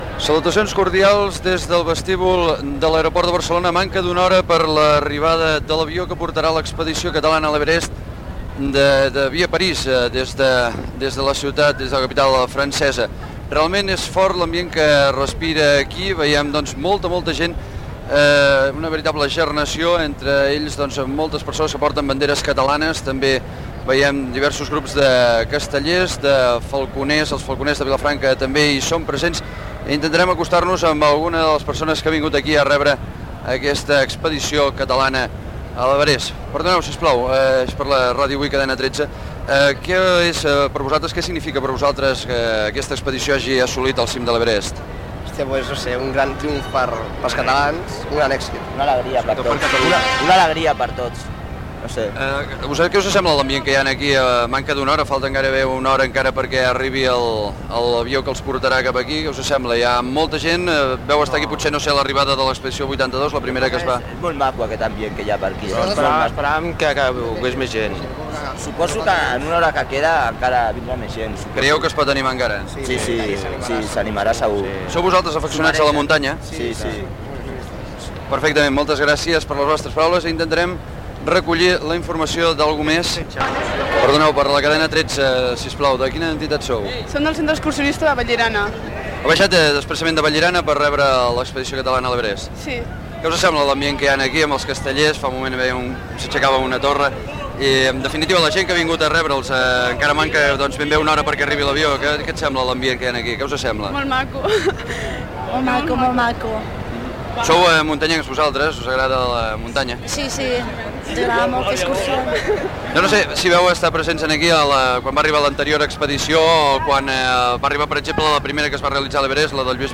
Connexió amb el vestíbul de l'aeroport del Prat de Barcelona, una hora abans de l'arribada de l'expedició catalana a l'Everest. Descripció de l'ambient i entrevistes informatives a algunes persones que s'hi congreguen.
Declaracions de l'alpinista Òscar Cadiach.
Informatiu